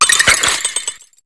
Cri de Sorboul dans Pokémon HOME.